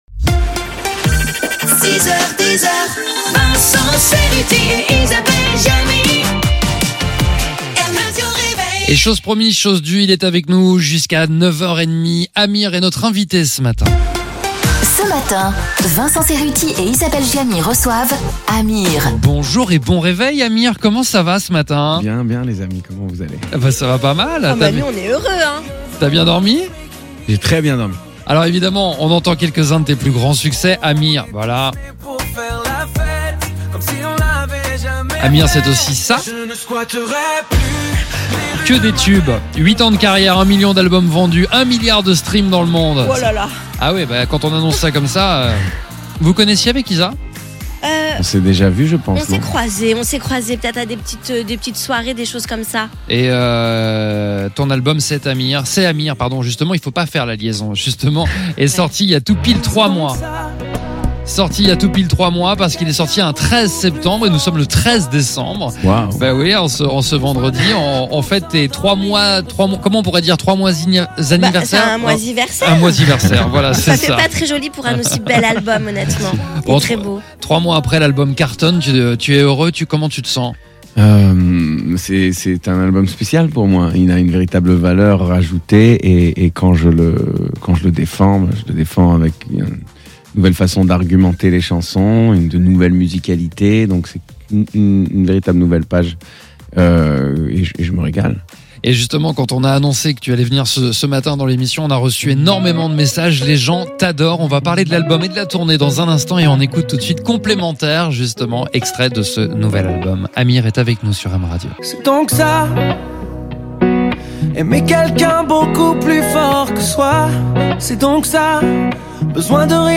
podcast-mrr-itw-amir-wm-80701.mp3